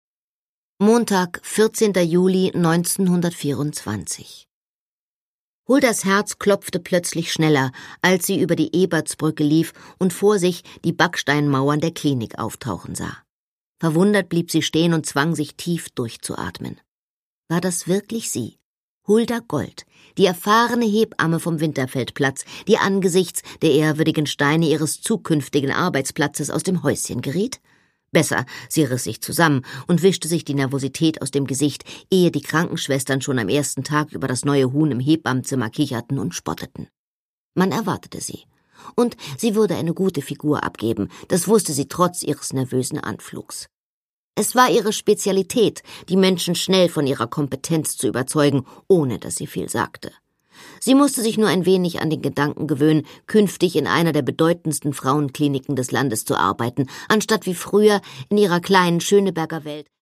Produkttyp: Hörbuch-Download
Gelesen von: Anna Thalbach